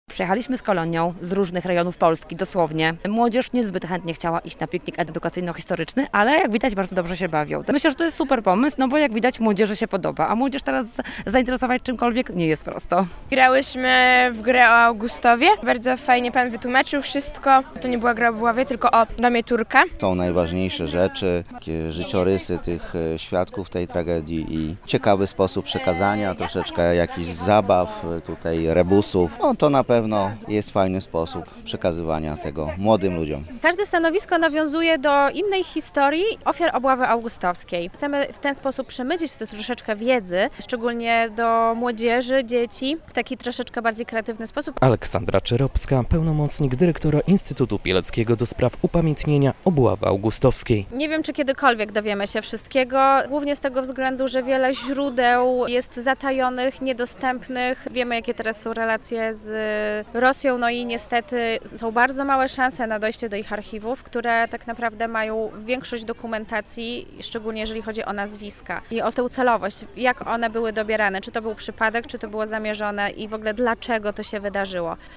Festiwal LIPCOWI'45 w Augustowie - relacja